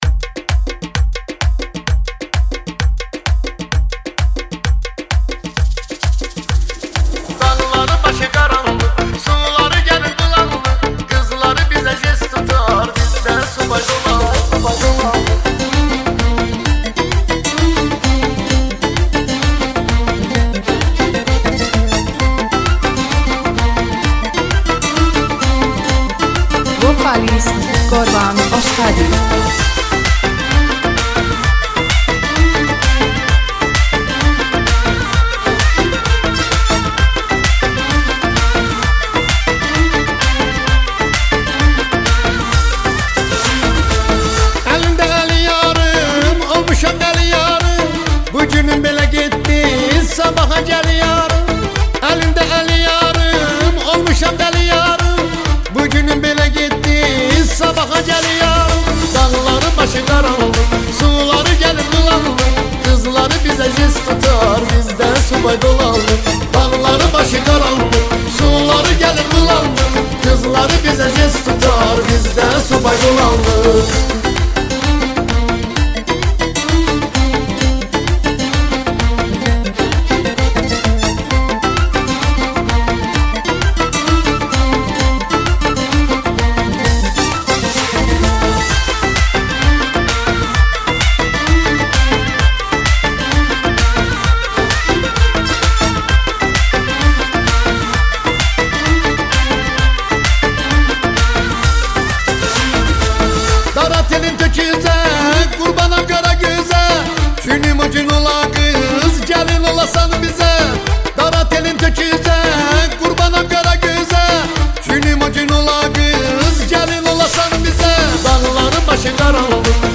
آهنگ های شاد آهنگ های محلی